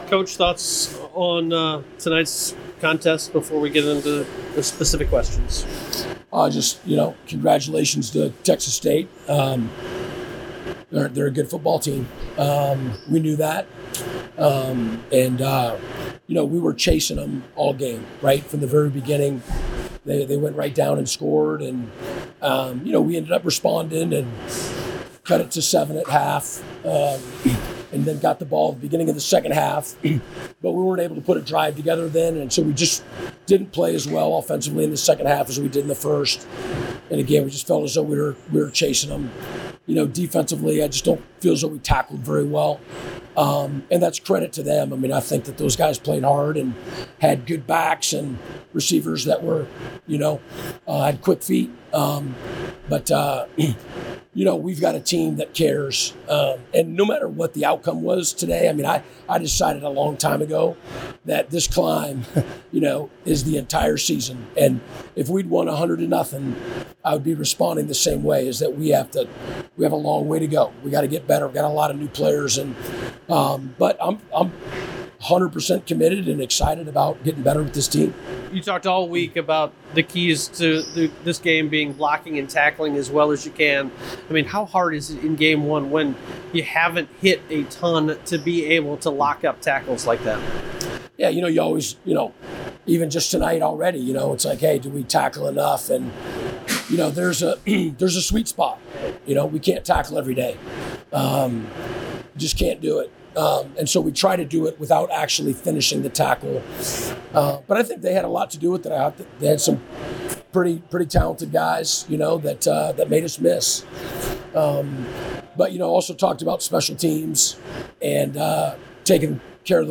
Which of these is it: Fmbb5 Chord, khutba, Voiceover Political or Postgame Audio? Postgame Audio